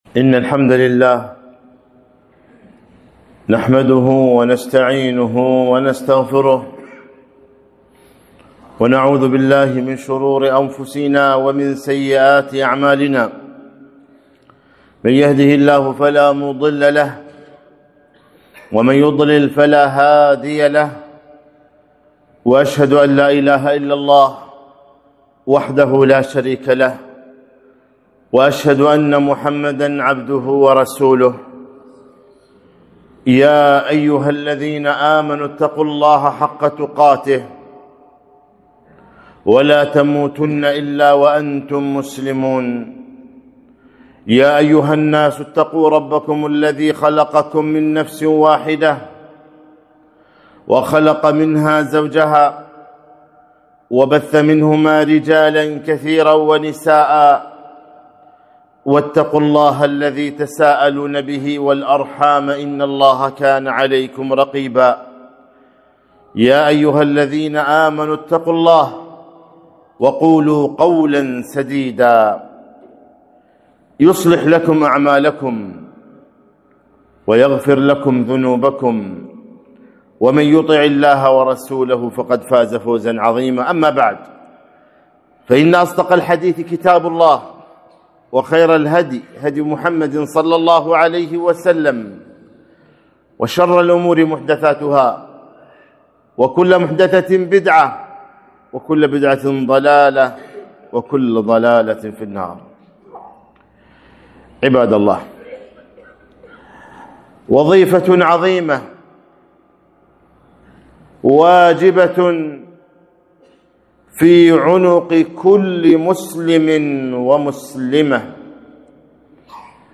خطبة - العبودية طريق السعادة الأبدية